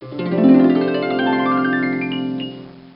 harp.wav